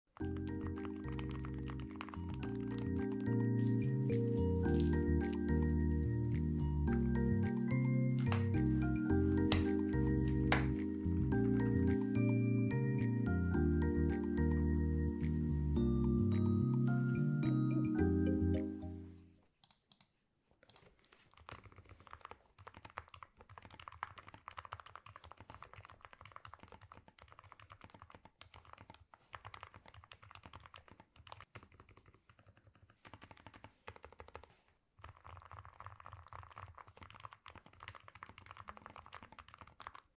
🤫 I see all of youuuu who want/NEED a silent or very quiet keyboard 💙 These feel like typing on marshmallows: soft and satisfying~ (*ᴗ͈ˬᴗ͈)*.ﾟIt’s quiet like when you’re underwater in the deep sea! 🌊 My partner types on this keyboard like a foot away from my head while I try to sleep and it’s a satisfying soft rumble, like asmr~ ☺ — Price: ~76 cents per switch!